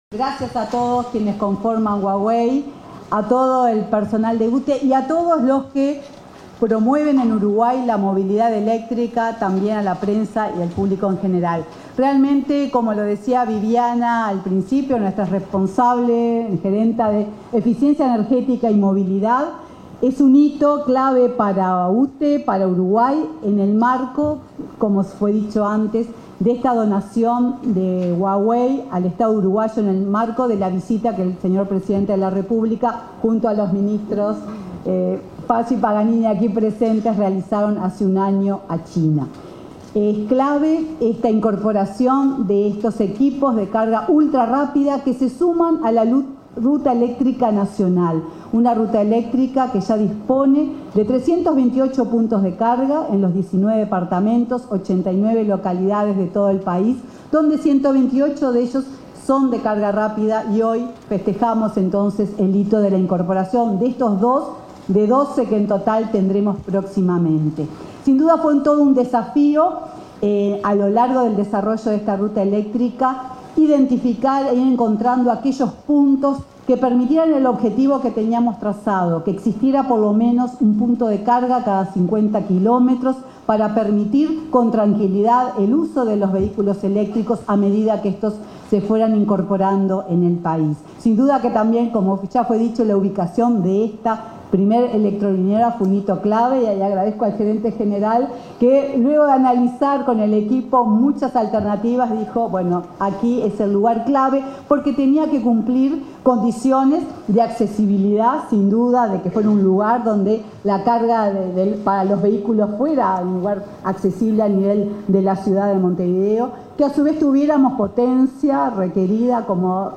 Palabras de la presidenta de UTE y la ministra de Industria, Energía y Minería
Palabras de la presidenta de UTE y la ministra de Industria, Energía y Minería 21/11/2024 Compartir Facebook X Copiar enlace WhatsApp LinkedIn En el marco de la inauguración de la primera electrolinera del país para carga de vehículos eléctricos, se expresaron la presidenta de UTE, Silvia Emaldi, y la ministra de Industria, Energía y Minería, Elisa Facio.